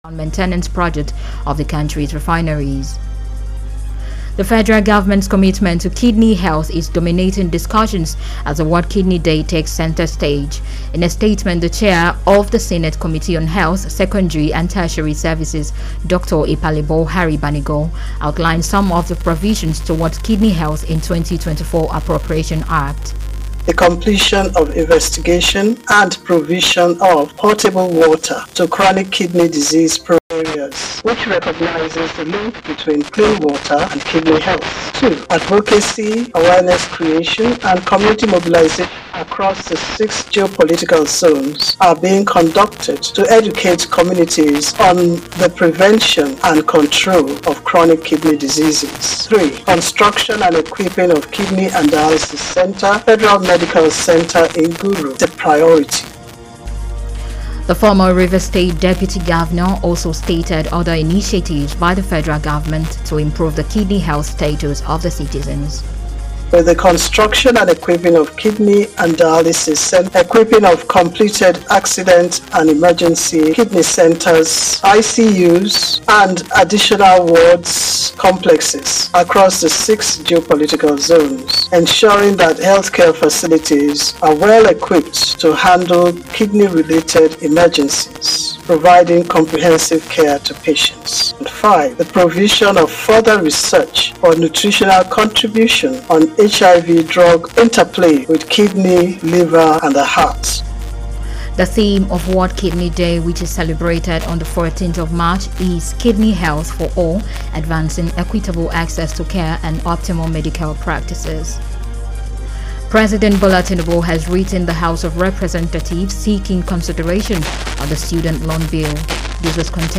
Senator-Ipalibo-Banigo-World-Kidney-Day-2024-Radio-Broadcast.mp3